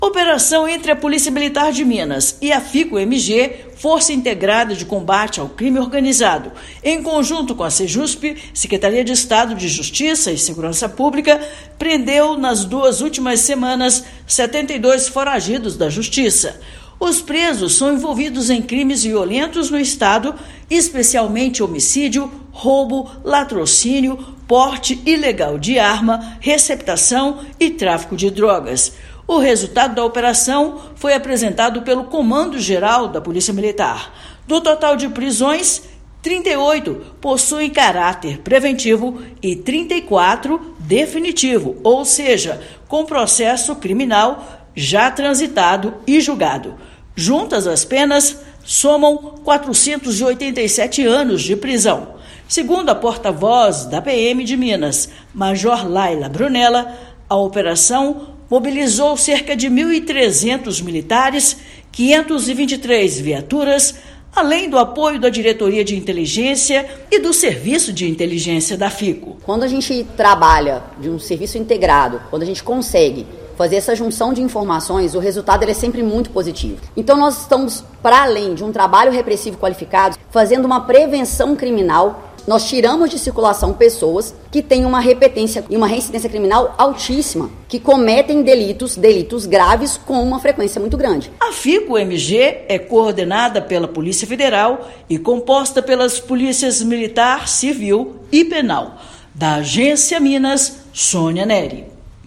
Presos são envolvidos em crimes violentos cometidos no estado, possuem 191 inquéritos e são indicados como autores em 620 boletins de ocorrência. Ouça matéria de rádio.